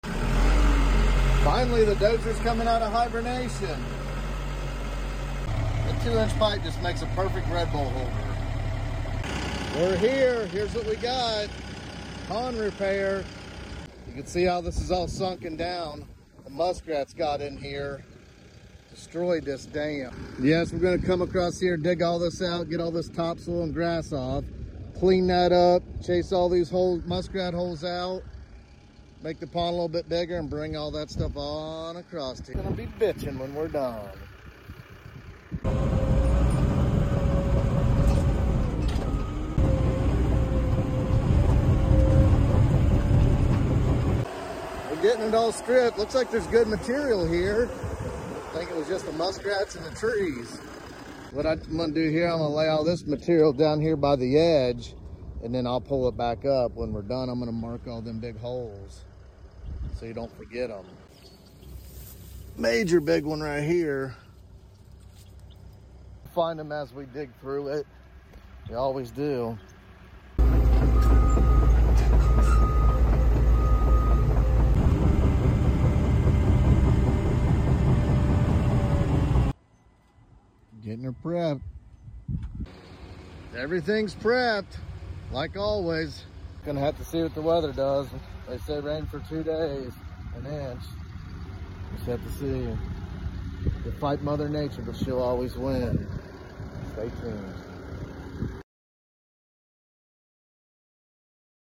starting on this pond repair with the Dozer and Excavator.